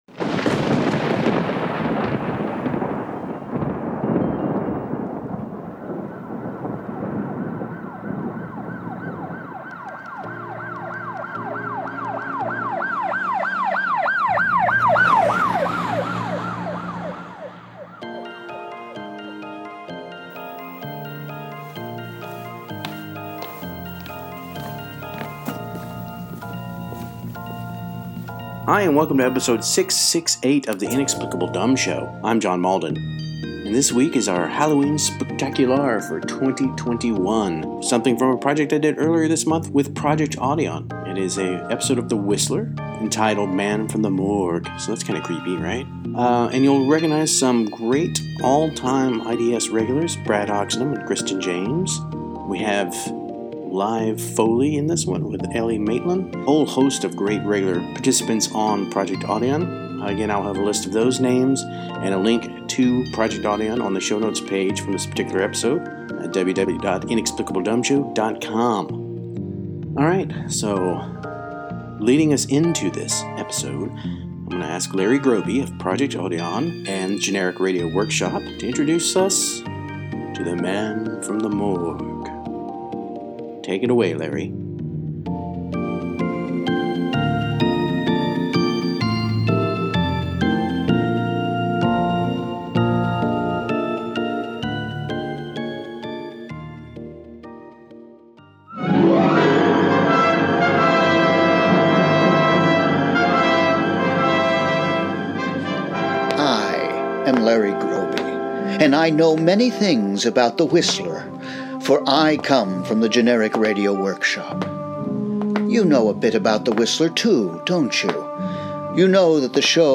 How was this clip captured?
Over 700 episodes were originally broadcast, but we’re recreating one that doesn’t survive: “Man from the Morgue” which aired January 13, 1952 and was since lost. Our coast-to-coast cast features outstanding performances all transcribed live via Zoom…Here they are: